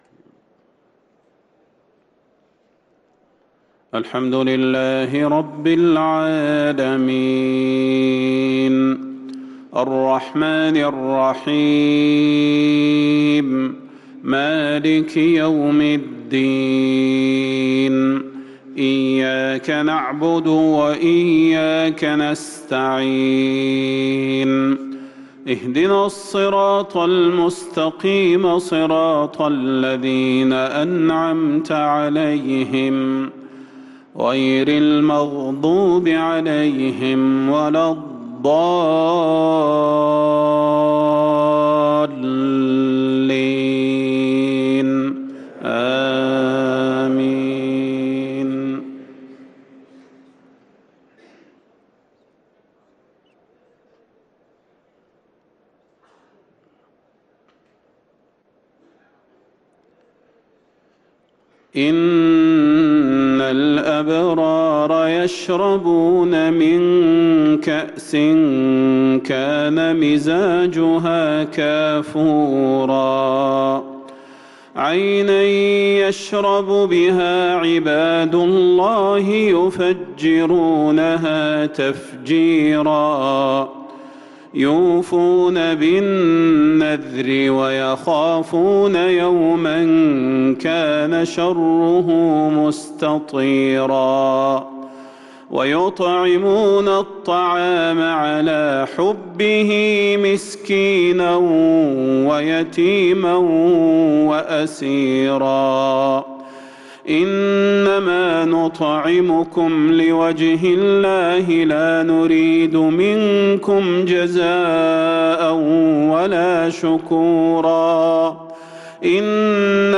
صلاة المغرب للقارئ صلاح البدير 4 ربيع الآخر 1444 هـ
تِلَاوَات الْحَرَمَيْن .